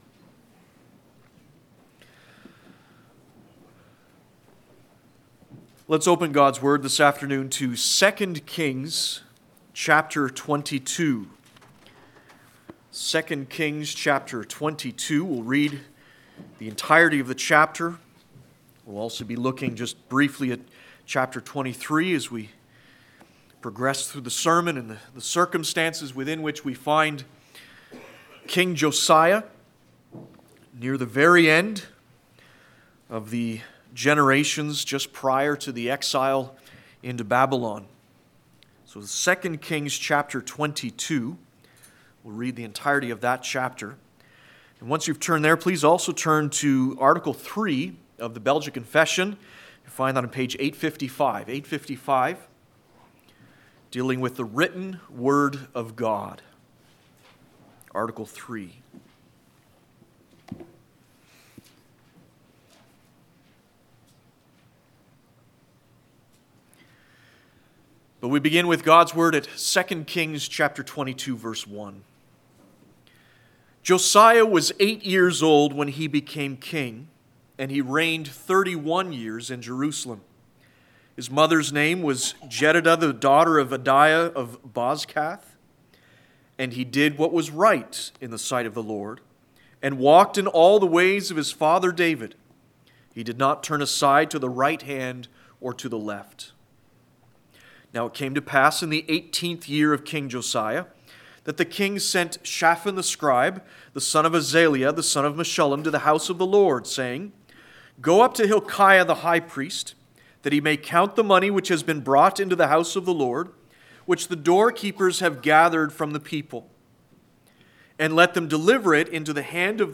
4.-Sermon-Hearing-Words-Tearing-Clothes.mp3